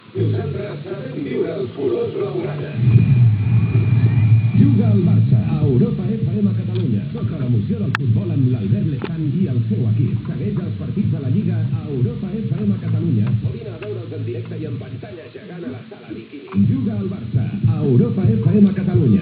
Promoció "Juga el Barça a Europa FM Catalunya"